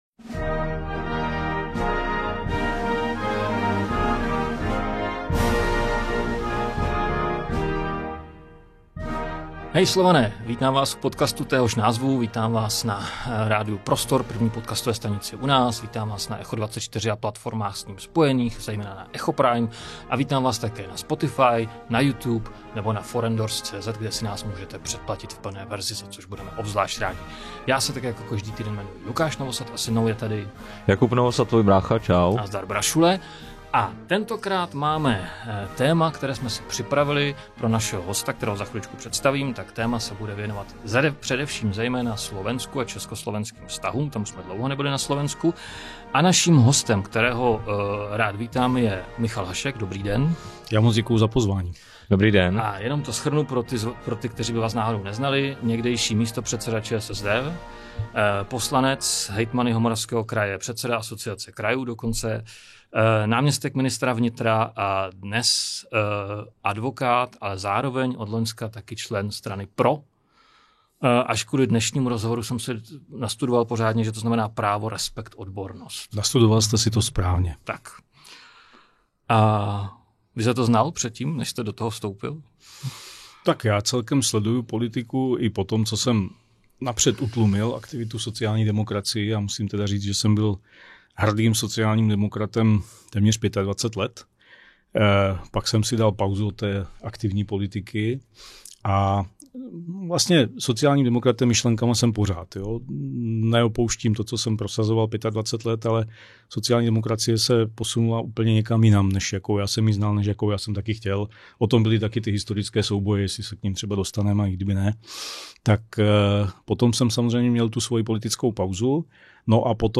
Rozhovor s Michalem Haškem o Robertu Fico jsme začali domlouvat před třemi týdny, mezitím ovšem se pozornost světa i Česka upnula k Ukrajině, Americe a Rusku. Vynechat v nové epizodě podcastu Hej, Slované tedy nelze nic z uvedeného – ostatně začínáme blahopřáním k narozeninám ukrajinského prezidenta.